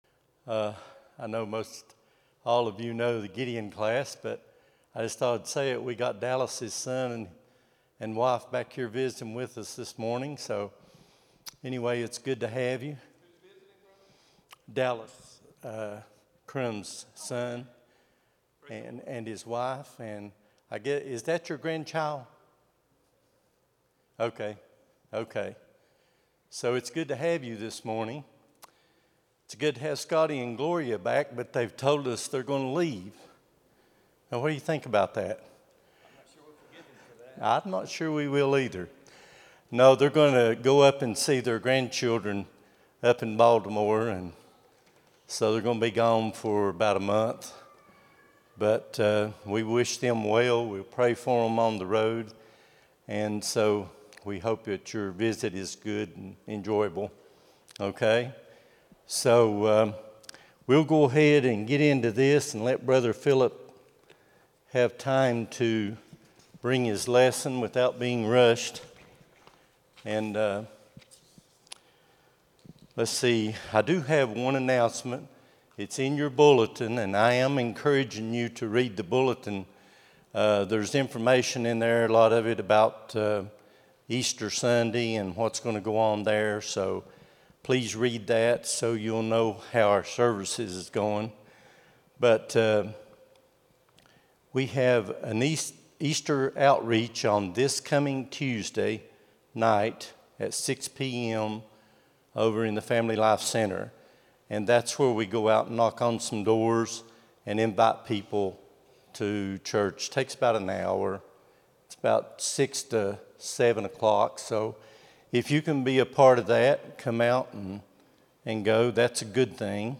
03-22-26 Sunday School | Buffalo Ridge Baptist Church